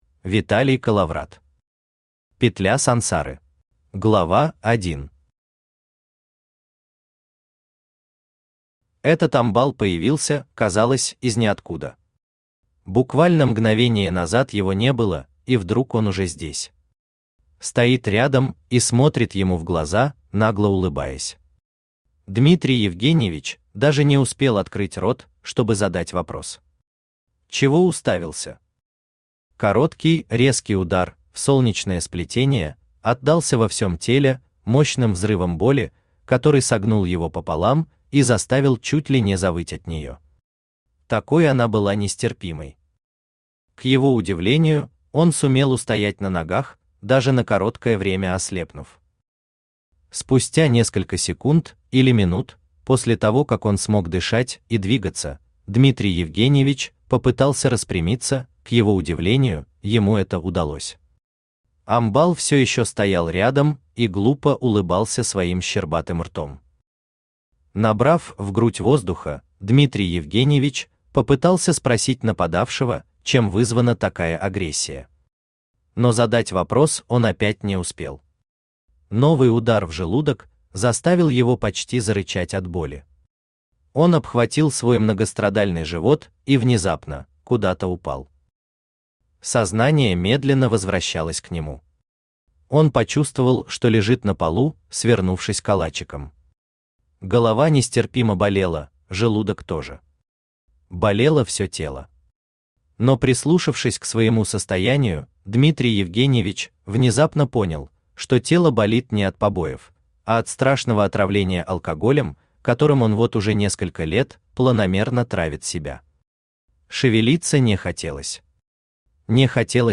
Аудиокнига Петля Сансары | Библиотека аудиокниг
Читает аудиокнигу Авточтец ЛитРес.